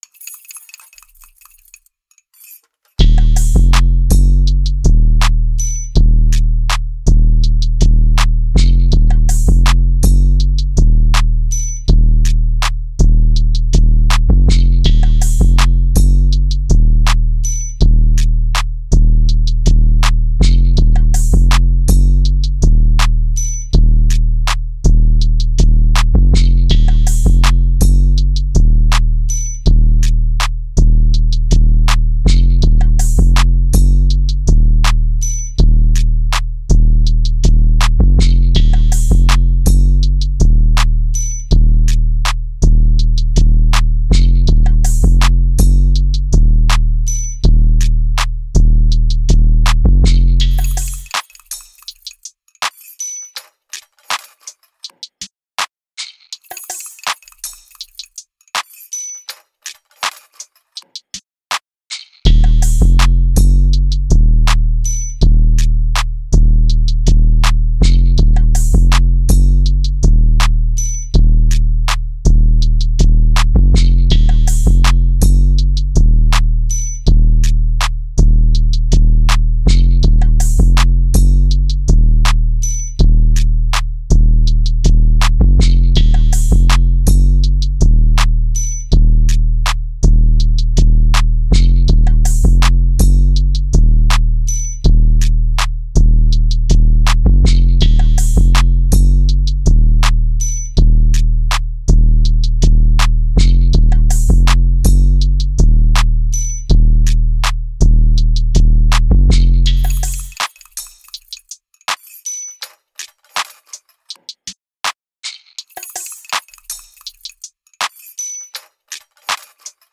Rap, Hip Hop, Trap
d#Minor